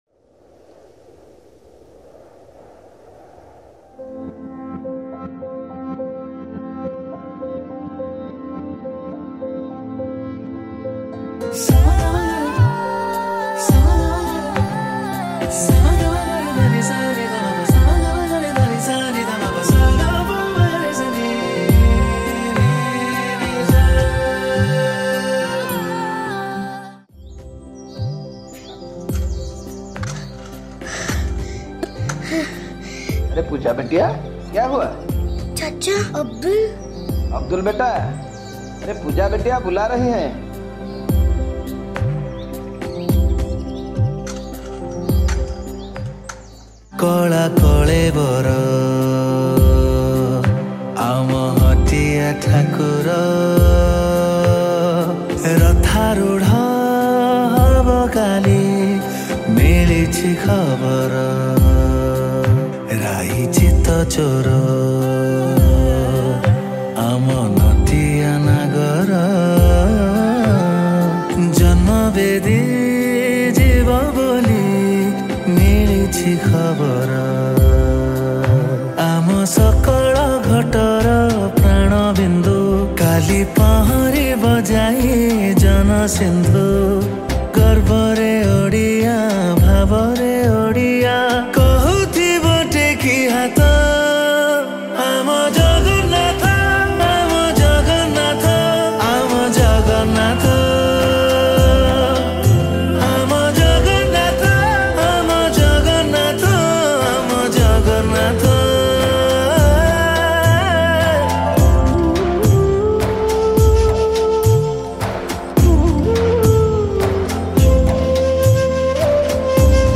Ratha Yatra Odia Bhajan 2023